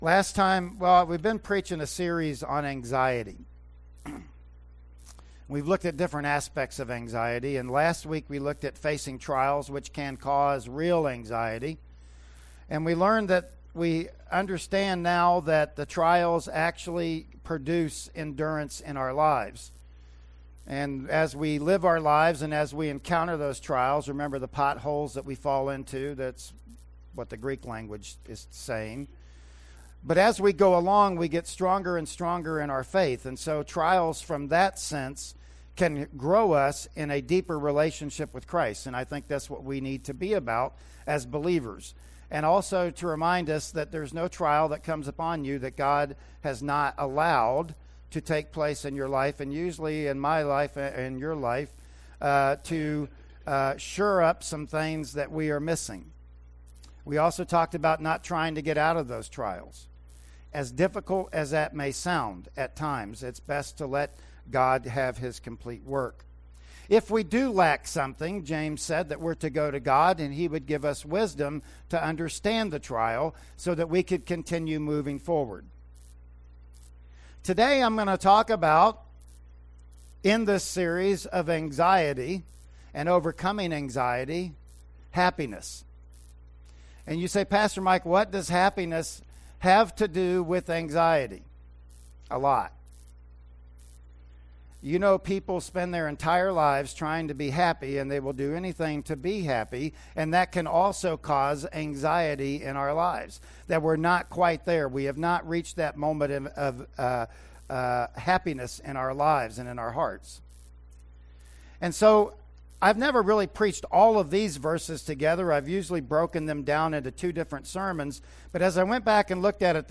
Service Type: Sunday Morning Worship Service